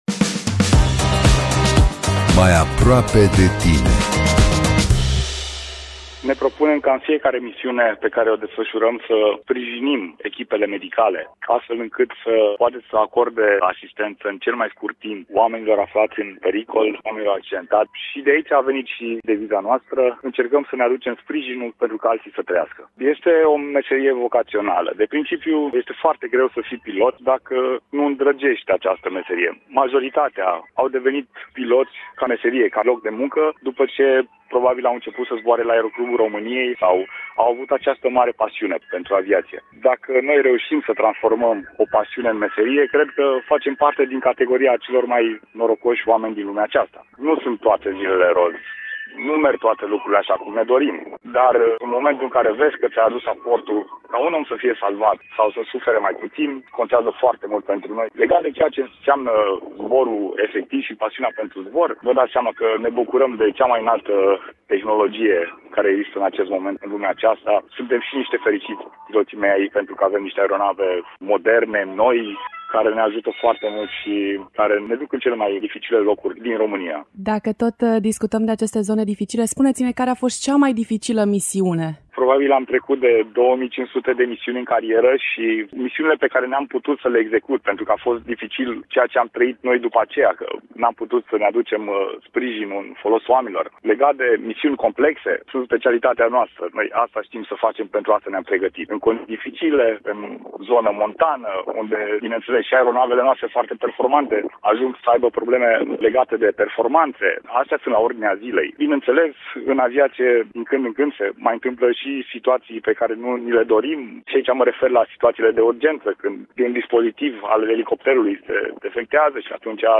În rubrica M.A.I. aproape de tine din această săptămână avem un interviu cu unul dintre oamenii specialiști, salvatori de profesie, care în fiecare zi zboară, la propriu, într-o cursă contra cronometru pentru viață.